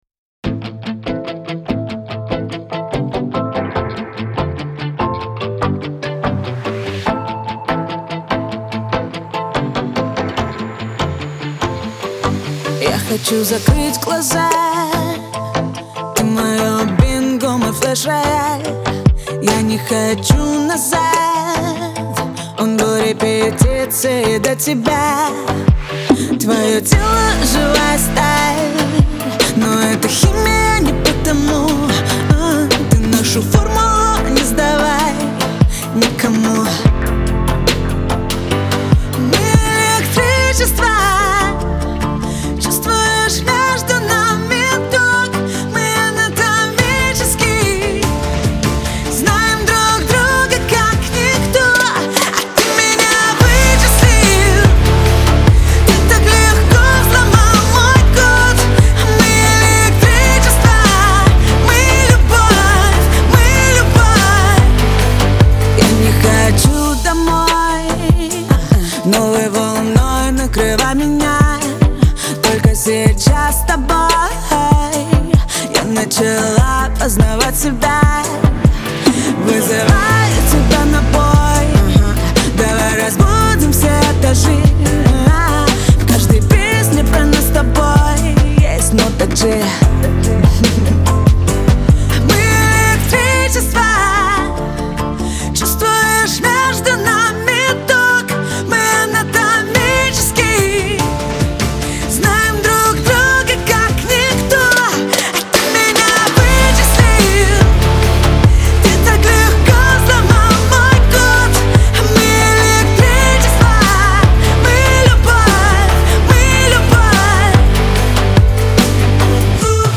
яркая и энергичная песня